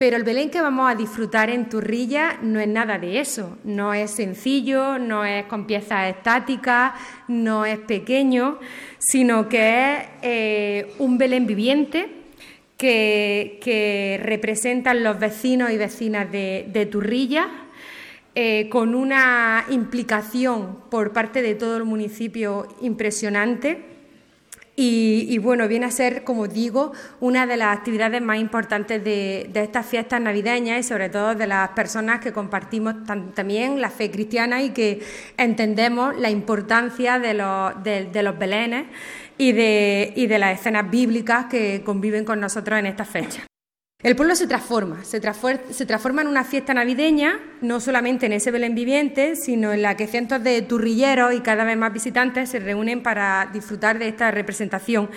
La diputada de Cultura, Cine e Identidad Almeriense, Almudena Morales, el alcalde de Turrillas, Antonio J. Segura, y la teniente de alcalde y concejal de Cultura, Encarnación Marín, han presentado esta actividad en el Palacio Provincial.
16-12_belen_viviente_turrillas_almudena_morales.mp3